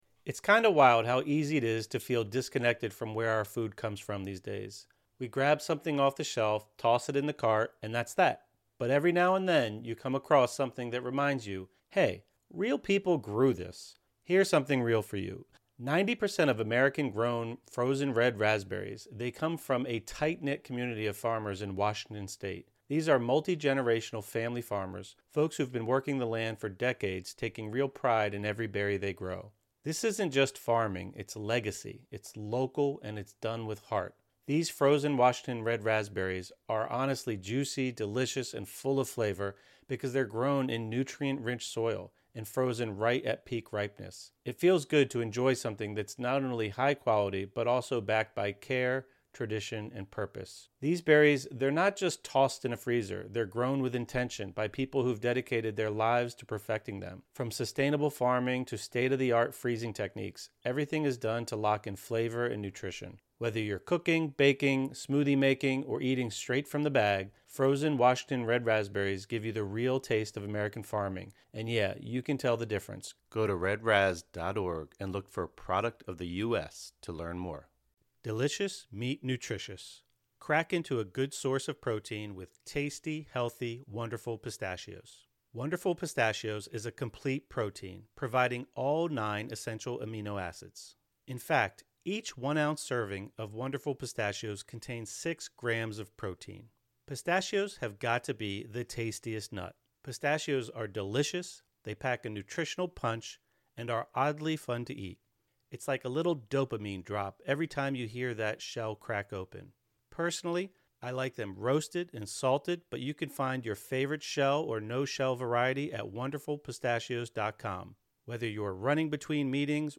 Unlocking Your Psychic Potential: A Conversation